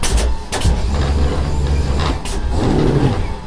Factory Sounds Lens Making Production Line
描述：This is the sound of a lens moulding production line. The pneumatic sounds are part of the automation. Recoded with an Edirol R09 in 320 kbps MP3 mode with the internal stereo microphones.
标签： machines pneumatics ambience factory fieldrecording
声道立体声